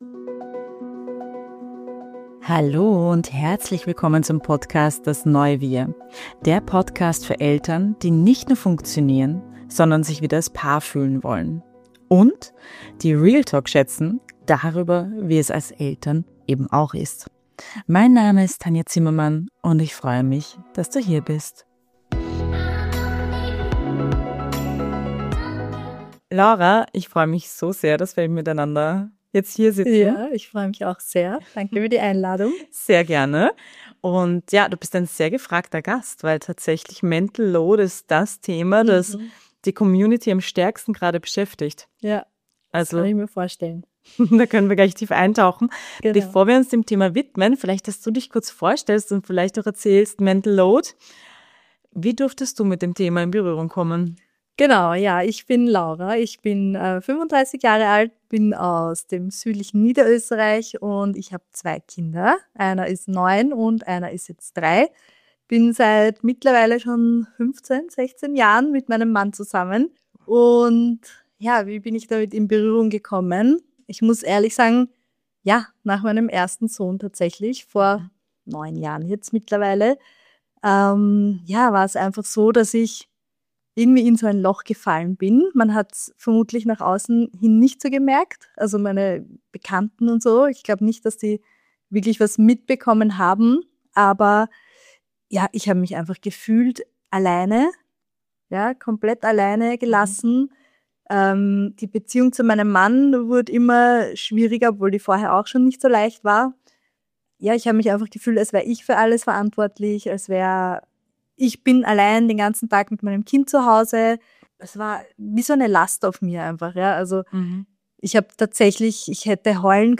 Ein ehrliches, tiefes Gespräch – ohne schnelle Lösungen, aber mit echten Antworten.